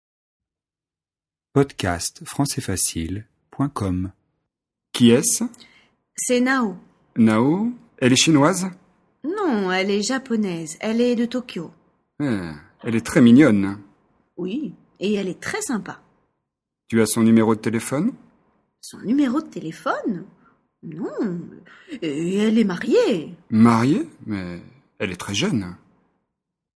Dialogue FLE et exercice de compréhension, niveau débutant (A1)